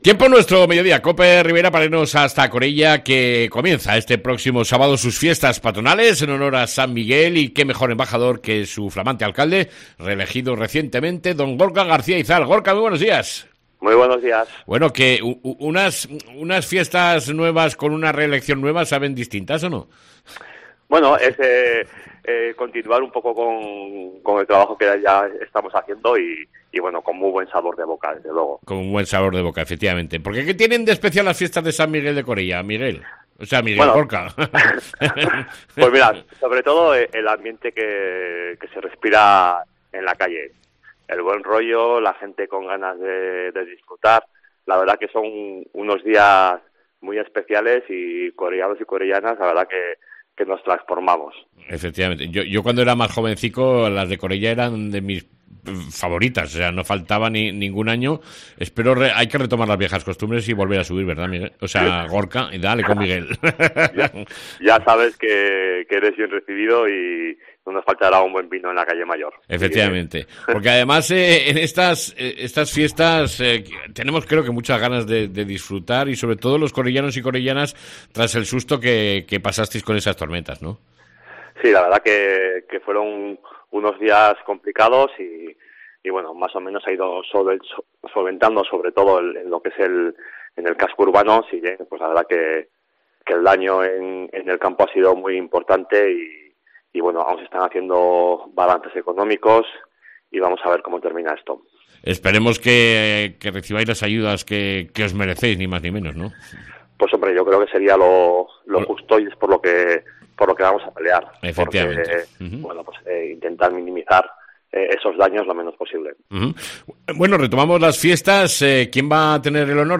ENTREVISTA CON EL ALCALDE DE CORELLA , GORKA GARCÍA IZAL